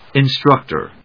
音節instruc・tor 発音記号・読み方
/ɪnstrˈʌktɚ(米国英語), ˌɪˈnstrʌktɜ:(英国英語)/